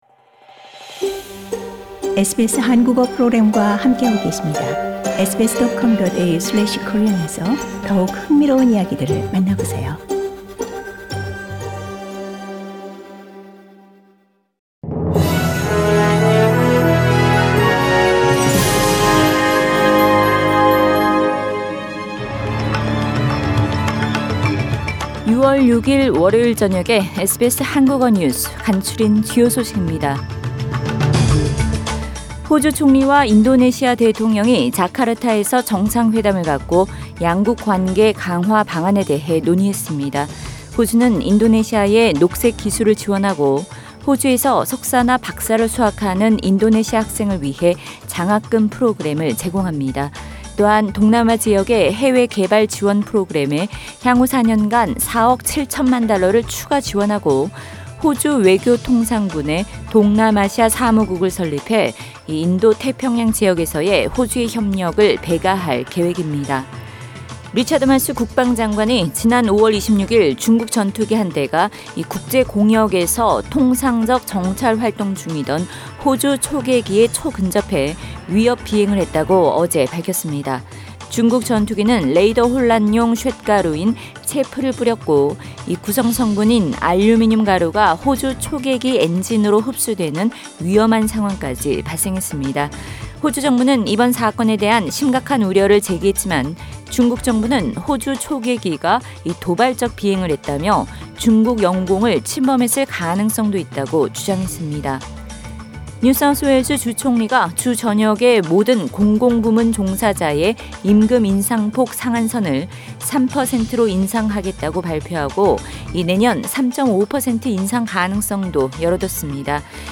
2022년 6월 6일 월요일 저녁 SBS 한국어 간추린 주요 뉴스입니다.